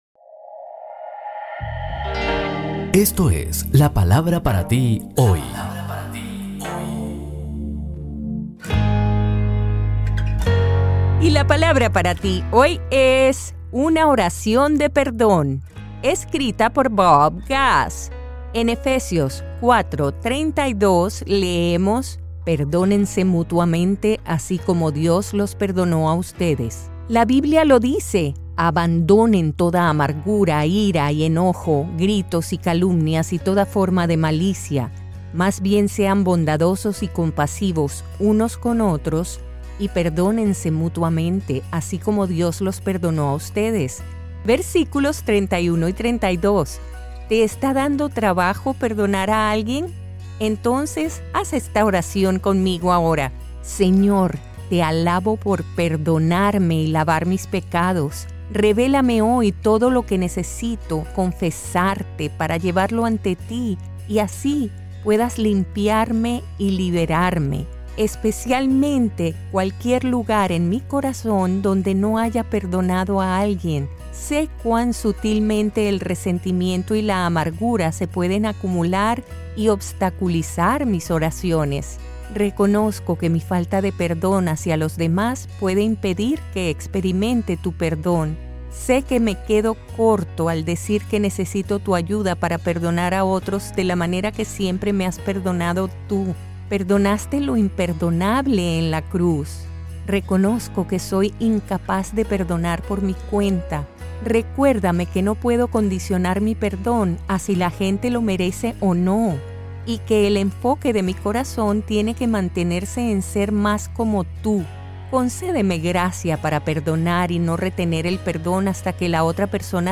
Es una oración que luego de reflexionar en alguna falta de perdón que puedas tener, la puedes repetir aquí con la dulce voz de Elluz Peraza. Esperamos que te ayude a perdonar, pues es de gran crecimiento para tu espíritu.